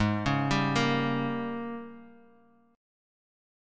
G#M7sus2sus4 Chord
Listen to G#M7sus2sus4 strummed